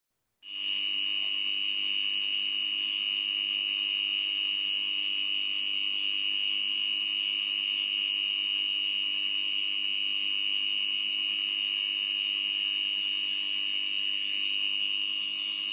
Вот аудиозапись помехи от него на УНЧ.